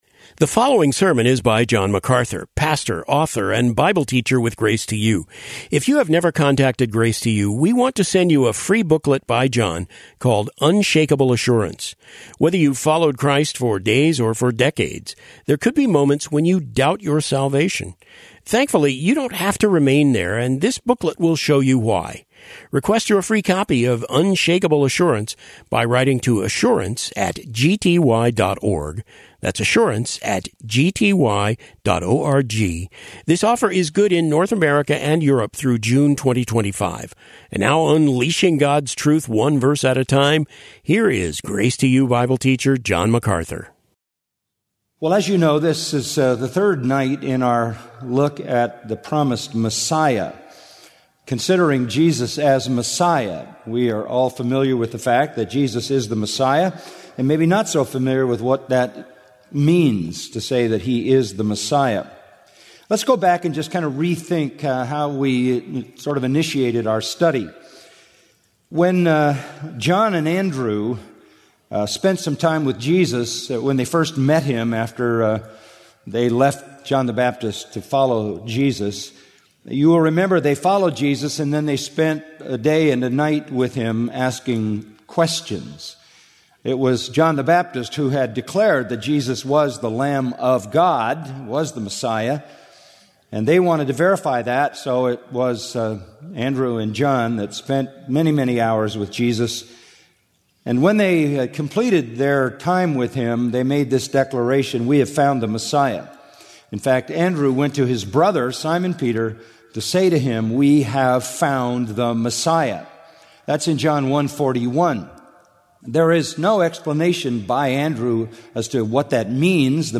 The following sermon transcript does not match the video version of the sermon—it matches only the audio version.